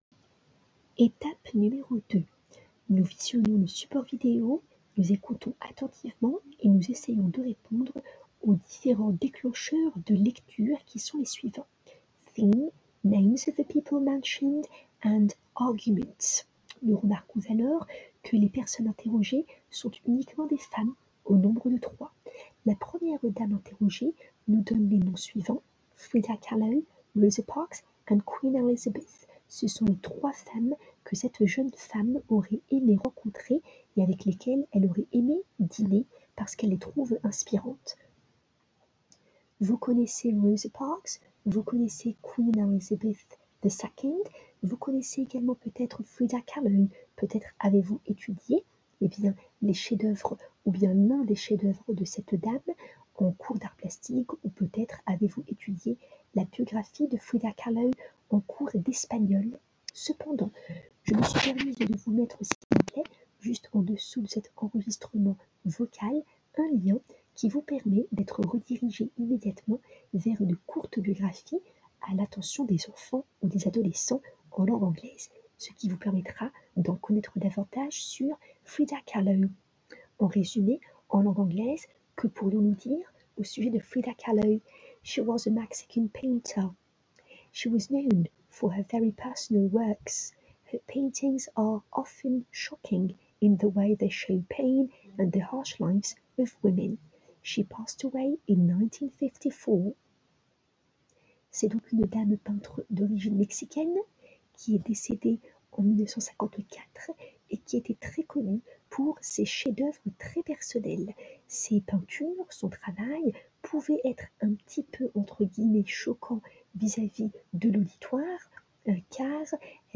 Je vous souhaite une très bonne écoute des pistes audio ci-dessous mettant en avant les explications orales du professeur relativement à la dernière leçon du chapitre 4 et à laquelle vous aviez à réfléchir, en classe inversée, juste avant la tâche finale du 30 mars 2020.
Audio 2 du professeur, d'une durée de 04:27: